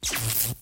Звук замыкания.ogg